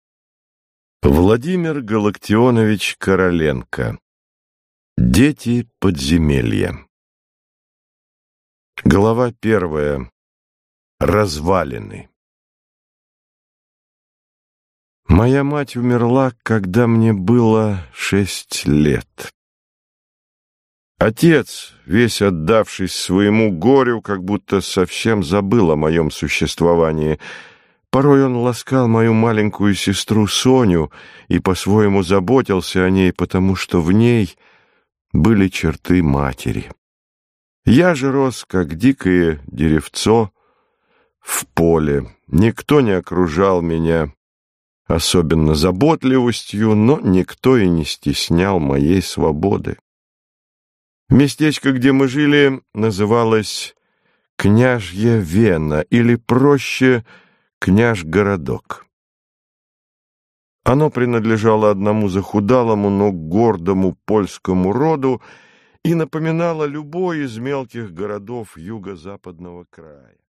Аудиокнига Дети подземелья | Библиотека аудиокниг